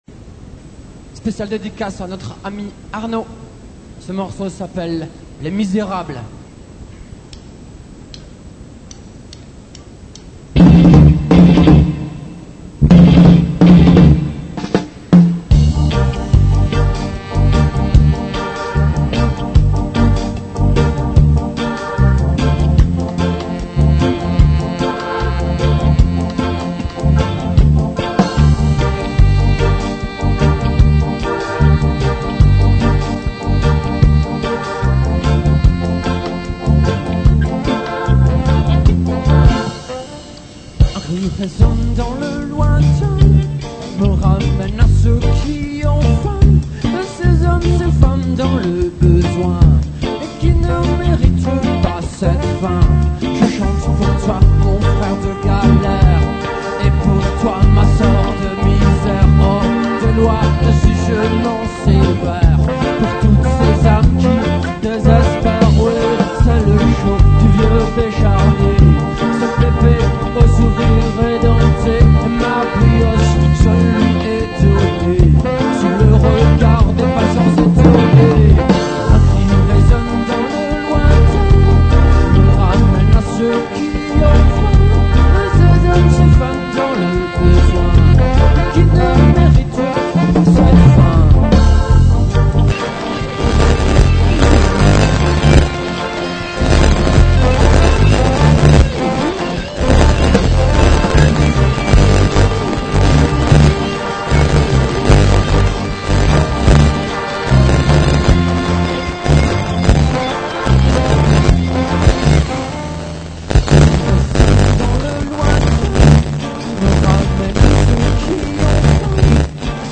reggea